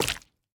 Minecraft Version Minecraft Version latest Latest Release | Latest Snapshot latest / assets / minecraft / sounds / block / honeyblock / break4.ogg Compare With Compare With Latest Release | Latest Snapshot